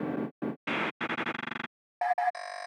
• 12 FX Loops: Add depth and dimension to your tracks with a variety of dynamic effects loops, perfect for transitions and creating atmospheric soundscapes.
2-FX-LOOP-179.wav